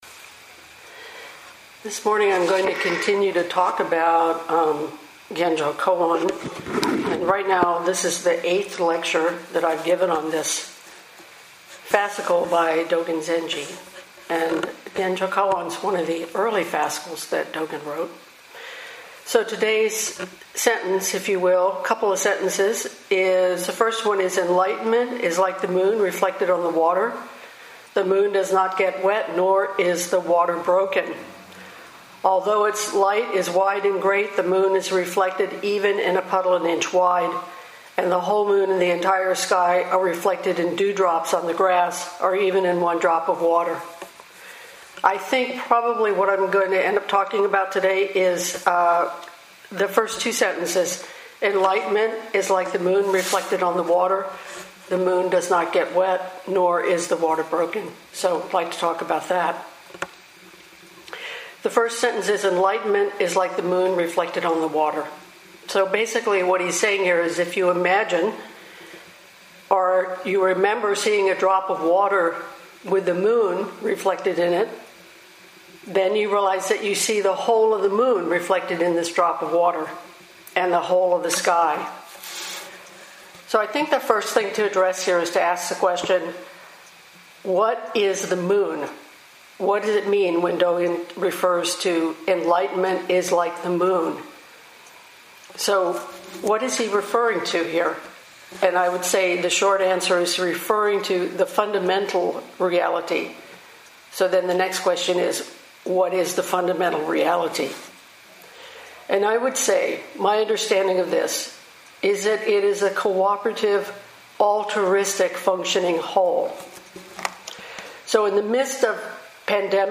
Shobogenzo Genjokoan Talk 8